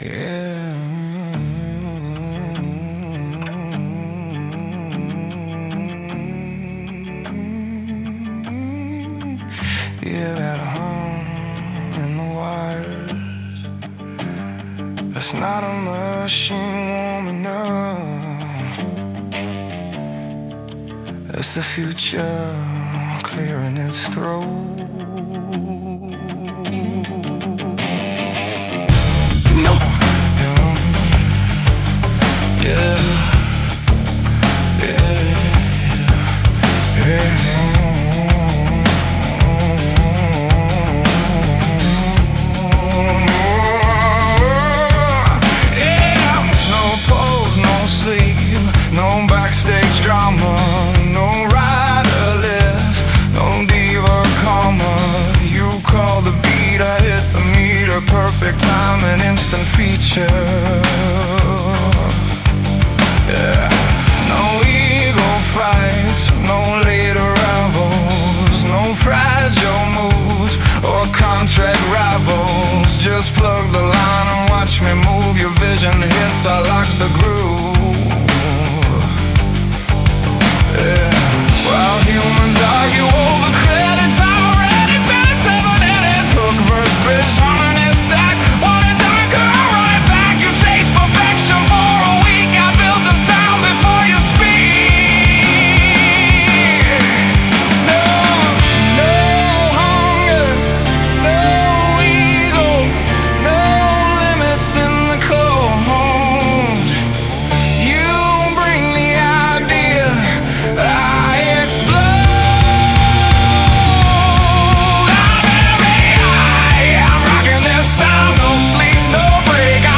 Every vocal on the track is 100% AI generated.
No human singer.
The track rejects clean modern polish in favor of something darker, heavier, and more human in texture.
It lives in a slow-burning space between soulful grunge, alternative art rock, and cinematic rock weight.
Distorted guitars carry long sustain, pressure, and layered atmosphere rather than glossy precision.
The bass feels melodic and grounding, holding the center while the arrangement expands around it.
The song’s power comes from contrast.
It builds.